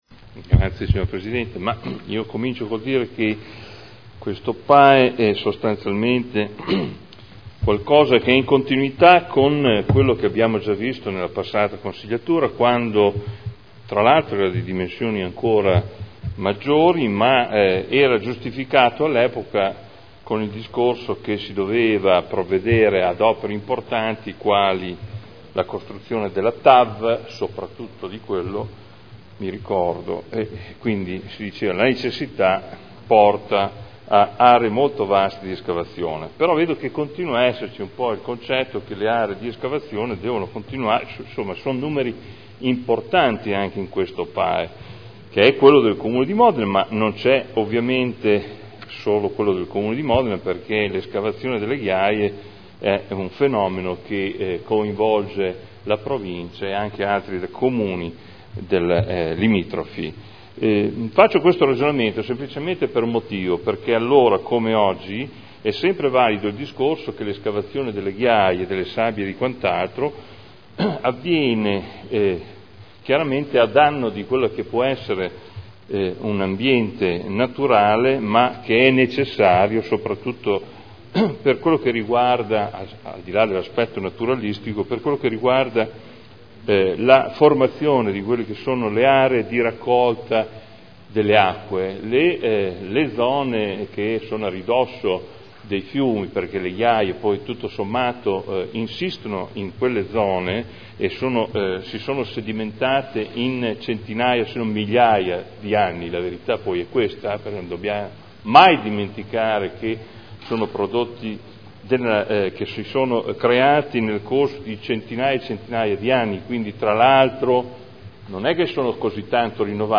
Adolfo Morandi — Sito Audio Consiglio Comunale
Seduta del 14/07/2011. Dibattito. Delibera: Approvazione dell’atto di indirizzo per l’attuazione del Piano delle Attività Estrattive del Comune di Modena (Commissione consiliare del 28 giugno 2011)